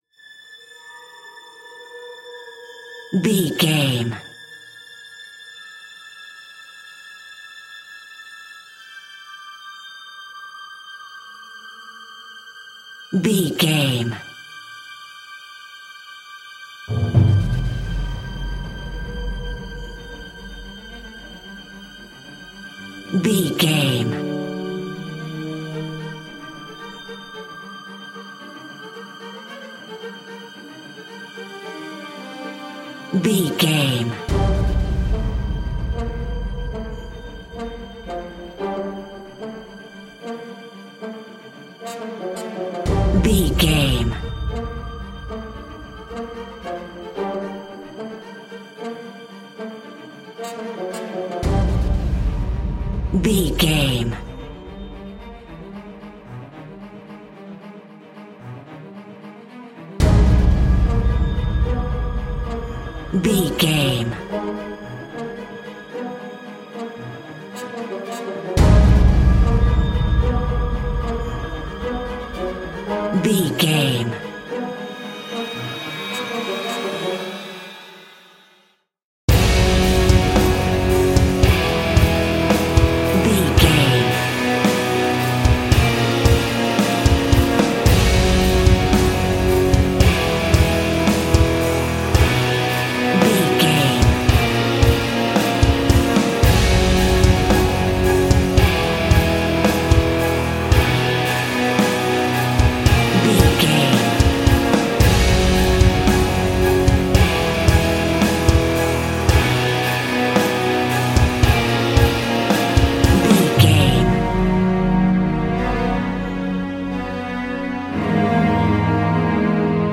In-crescendo
Thriller
Aeolian/Minor
G#
Fast
tension
ominous
dark
strings
brass
drums
electric guitar
cinematic
orchestral
fast paced
cymbals
taiko drums
timpani